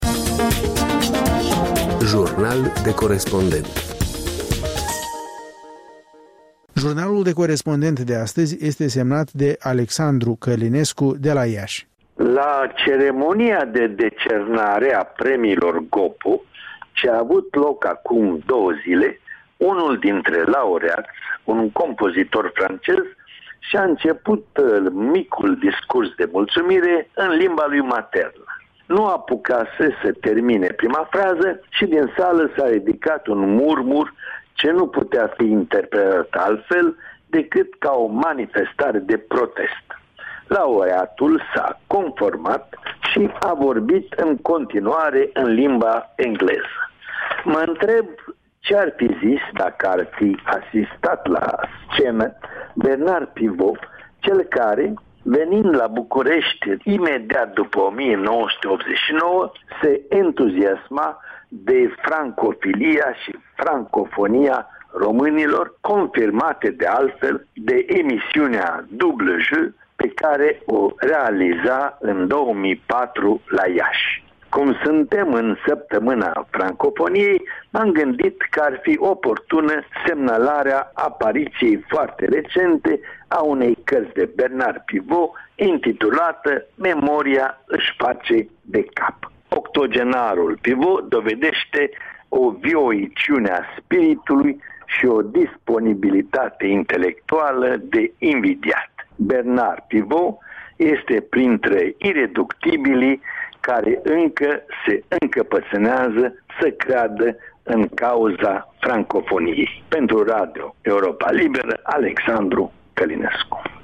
Jurnal de Corespondent